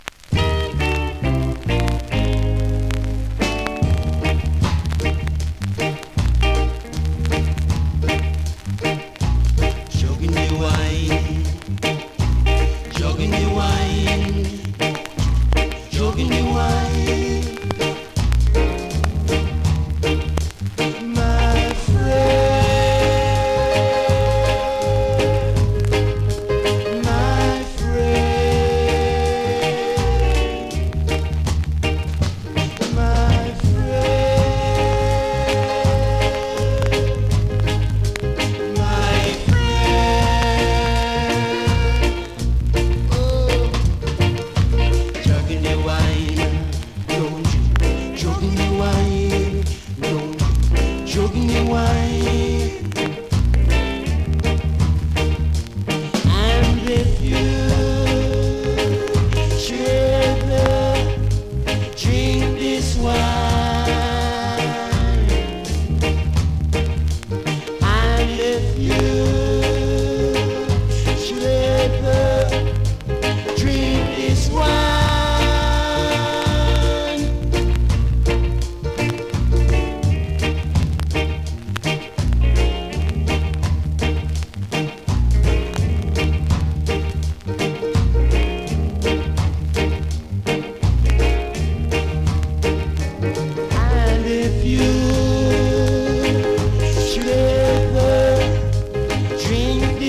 スリキズ、ノイズそこそこあります。